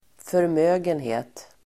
Uttal: [förm'ö:genhe:t]